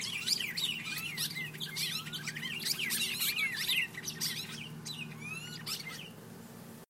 鸟儿的鸣叫
描述：早晨醒来时鸟儿的声音。在唧唧喳喳和吹口哨之间交替。
标签： 性质 鸟的鸣叫 OWI 啁啾
声道立体声